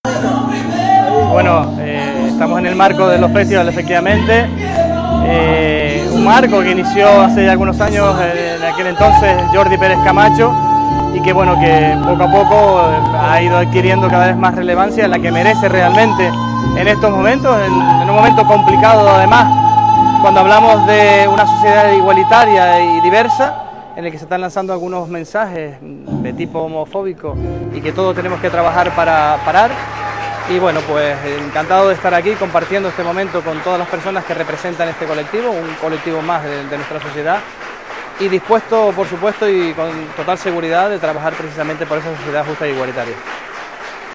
Declaraciones_audio_Sergio_Rodríguez_manifiesto_LGTBIQ_.mp3